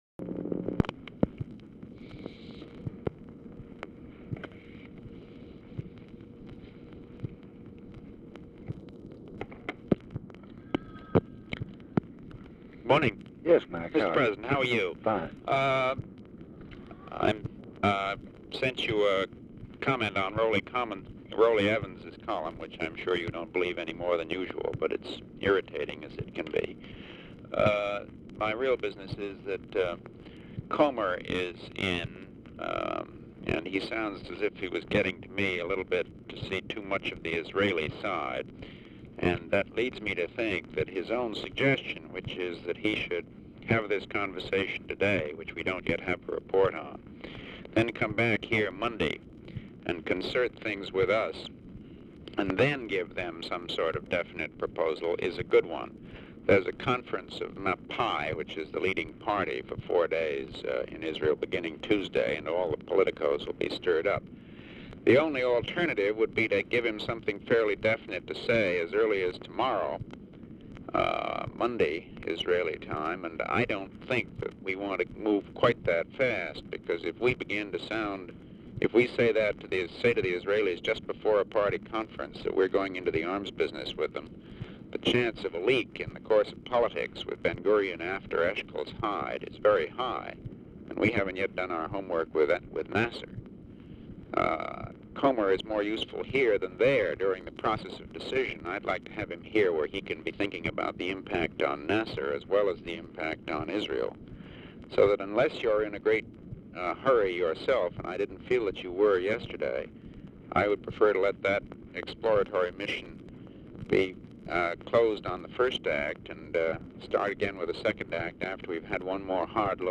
Telephone conversation # 6834, sound recording, LBJ and MCGEORGE BUNDY, 2/14/1965, 1:02PM | Discover LBJ
Format Dictation belt
Location Of Speaker 1 Mansion, White House, Washington, DC